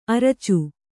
♪ aracu